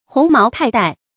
鴻毛泰岱 注音： ㄏㄨㄥˊ ㄇㄠˊ ㄊㄞˋ ㄉㄞˋ 讀音讀法： 意思解釋： 見「鴻毛泰山」。